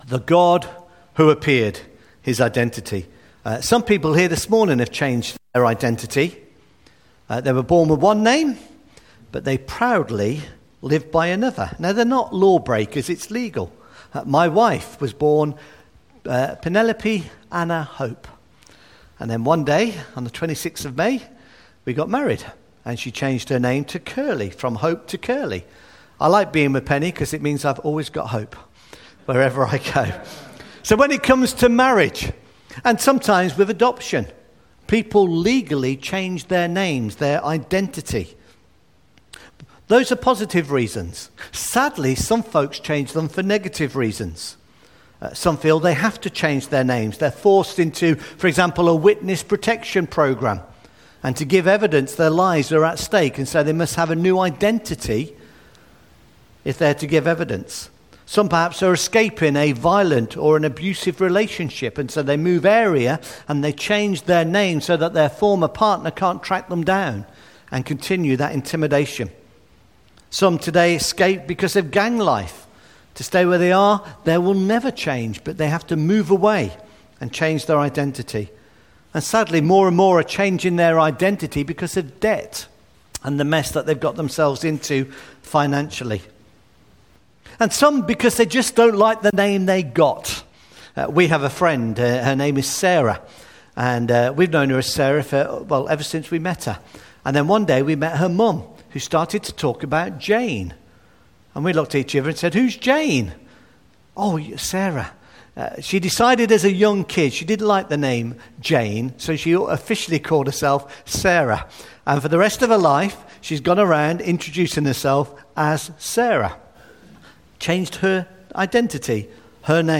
Note: My apologies for the poor sound quality on this recording.